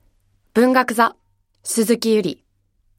ボイスサンプルはこちら↓